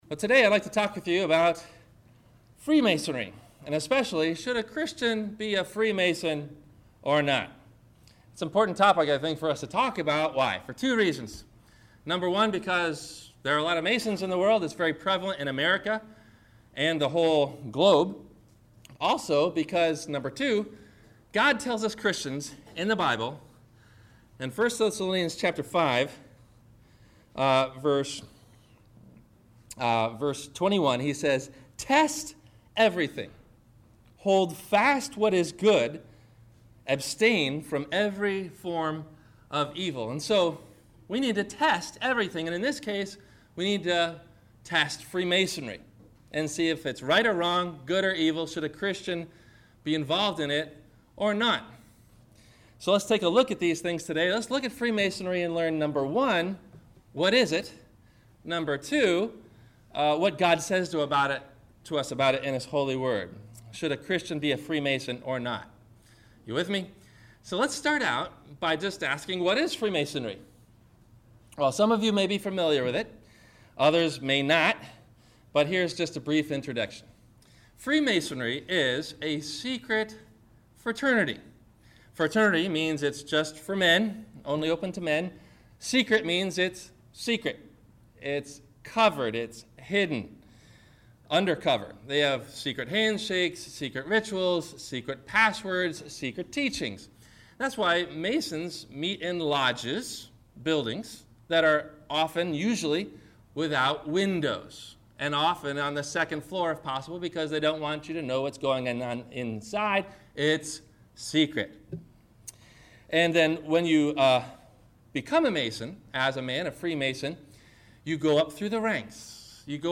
Listen for these questions and answers about Should Evangelism Still Emphasize The Wrath of God?, below in the 1-part Video or 1-part MP3 Audio Sermon below.